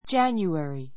January 小 A1 dʒǽnjueri ヂャ ニュエリ ｜ dʒǽnjuəri ヂャ ニュアリ 名詞 1月 ⦣ Jan.